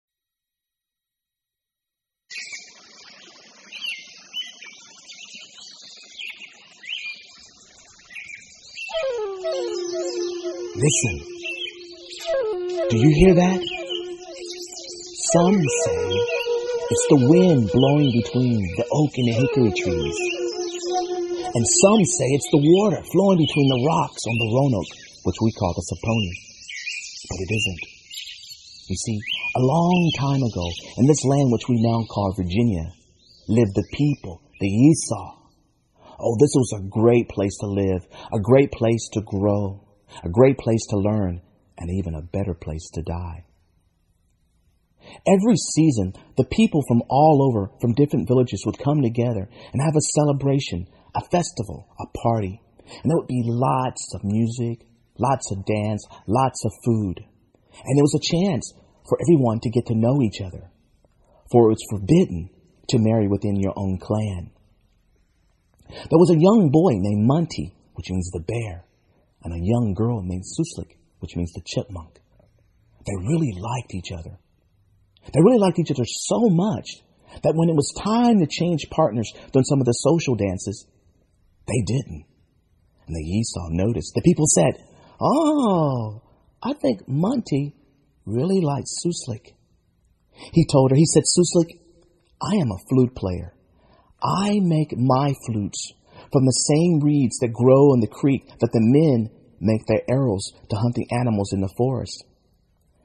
Native American Flute Music and More